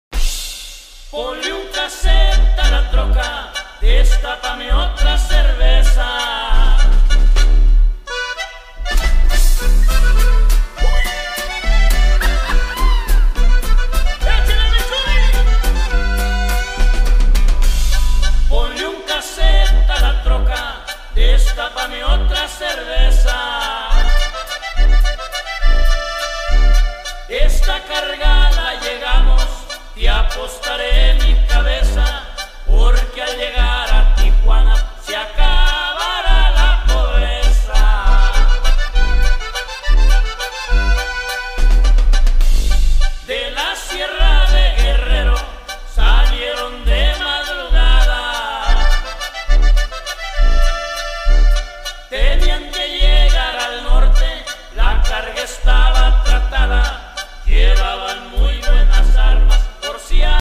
"EPICENTER BASS"